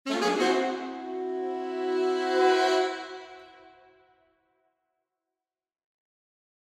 … gibt es noch eine Reihe kurzer Läufe, Bendings …